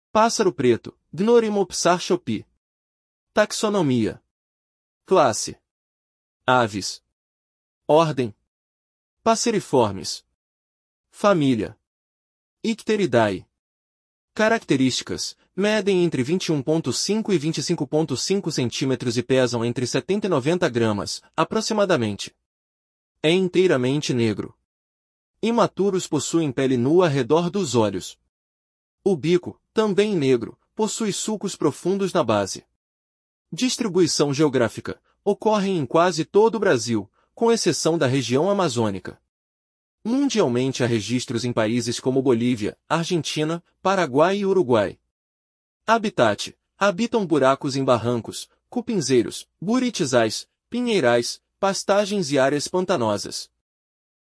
Pássaro-preto (Gnorimopsar chopi)